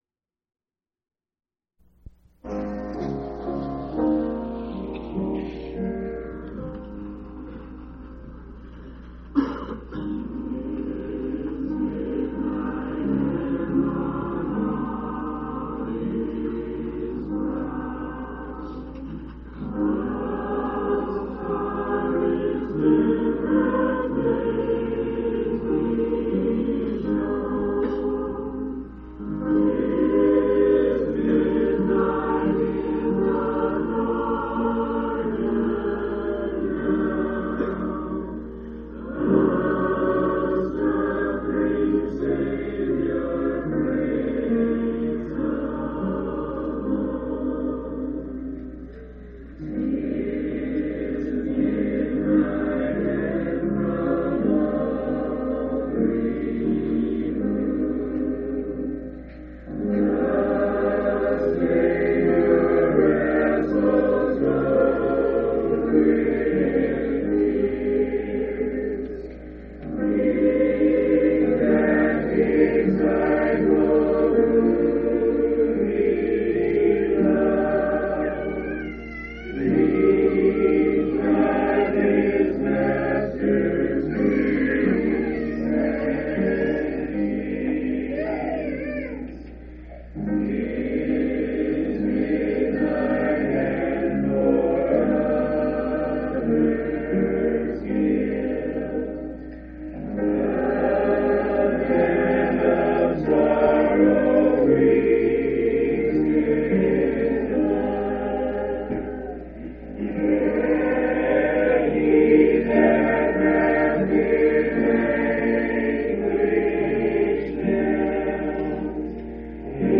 3/26/1989 Location: Phoenix Local Event